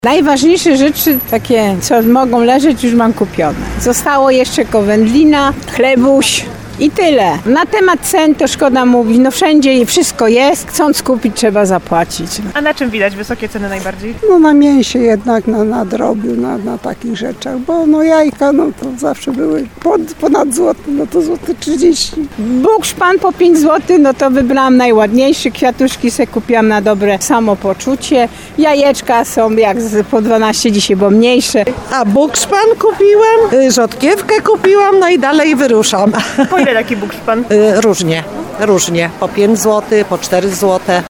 Spotkani przez Radio RDN Małopolska na 'Burku” mieszkańcy mówili, że produkty, które można przechowywać dłużej, starali się kupić już jakiś czas temu, 'polując’ na promocje.
31sonda-burek.mp3